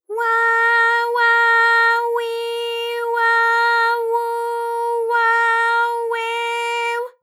ALYS-DB-001-JPN - First Japanese UTAU vocal library of ALYS.
wa_wa_wi_wa_wu_wa_we_w.wav